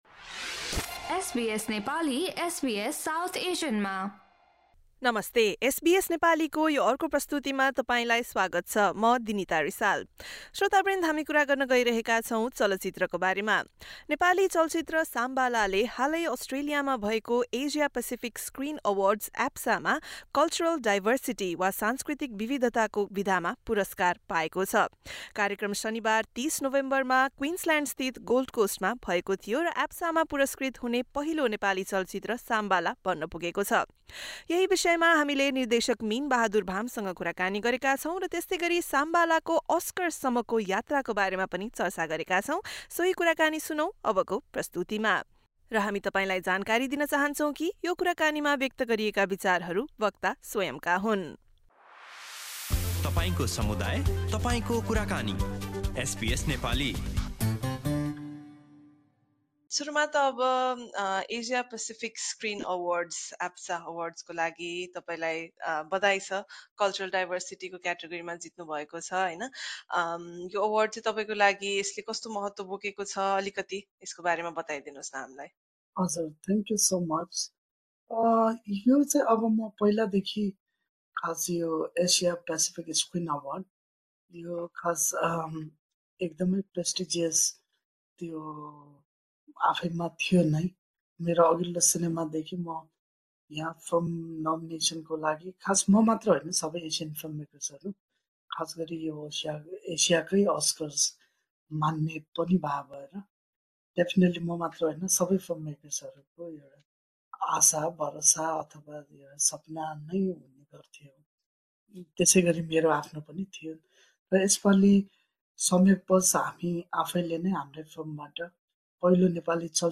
एसबीएस नेपालीसँग गरेको कुराकानी सुन्नुहोस्।